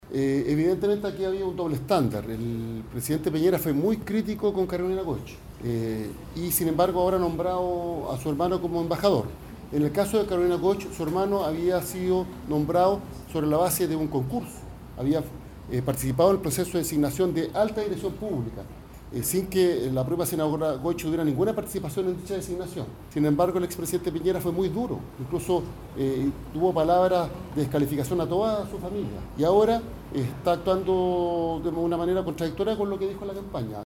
Con todo el exvocero del gobierno anterior -y hoy presidente del Partido Socialista (PS)- senador Álvaro Elizalde, acusó a Sebastián Piñera de ser una persona doble estándar.